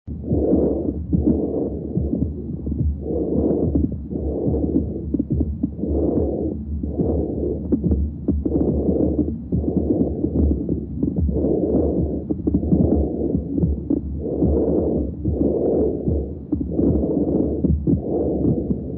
支气管呼吸音伴大湿啰音
coarse rales